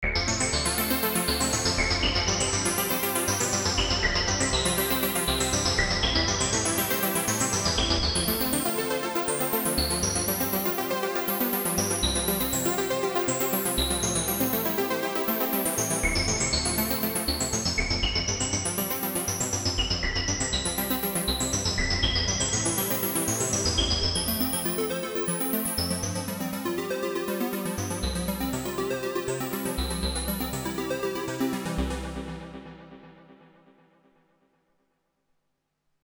нарулил сегодня на джуне патч с обворожительным живым и кристальным резонансом.
Интересно на вирусе попробовать что-то подобное нарулить ) Вложения Juno60 reso.mp3 Juno60 reso.mp3 1,4 MB · Просмотры: 1.502